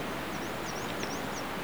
Given its very high and faint song, it is easiest to observe in late April combing branches for insects just before the leaves have unfolded and prior to the arrival of other much louder birds.